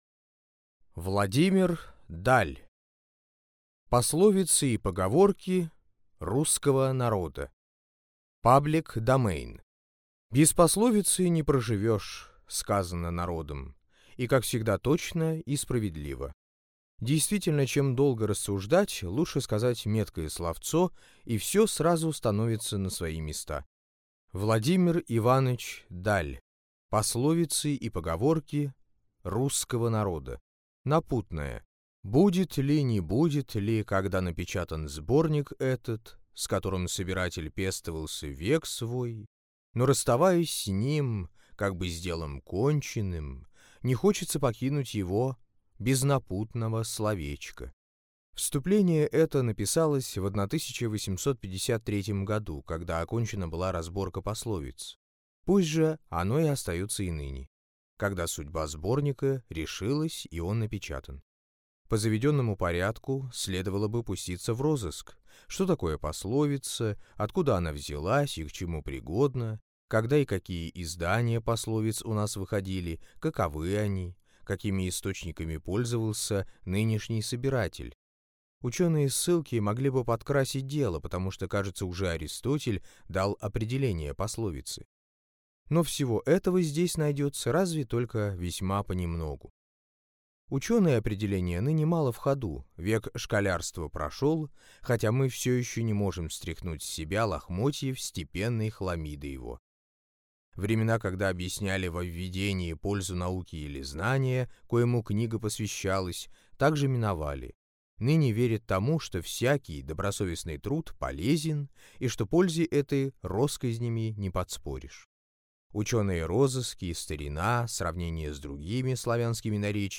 Аудиокнига Пословицы и поговорки русского народа | Библиотека аудиокниг